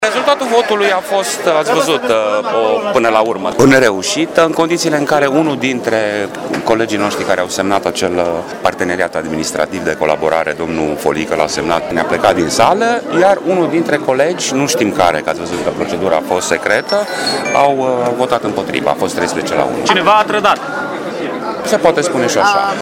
PSD, prin vocea consilierului Radu Țoancă, și-a asumat înfrângerea: